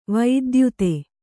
♪ vaidyute